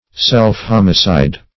Search Result for " self-homicide" : The Collaborative International Dictionary of English v.0.48: Self-homicide \Self`-hom"i*cide\, n. The act of killing one's self; suicide.